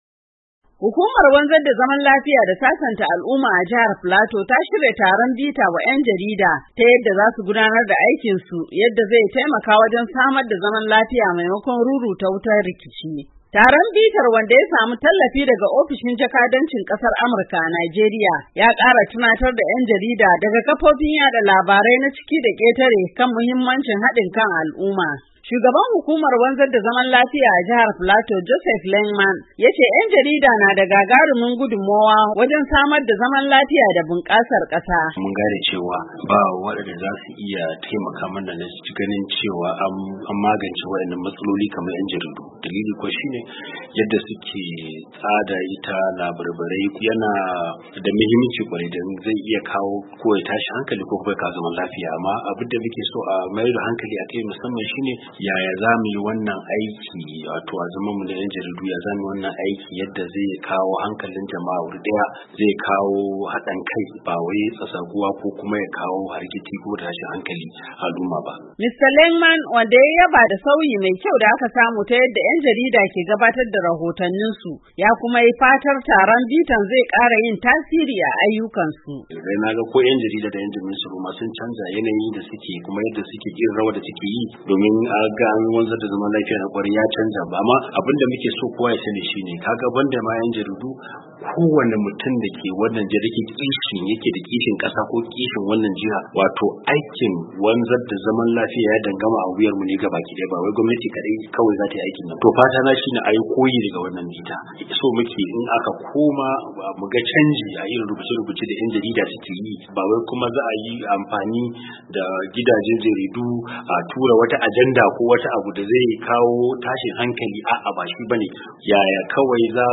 Mahalarta taron da Sashen Hausa ya yi hira da su sun bayyana cewa, sun karu sosai.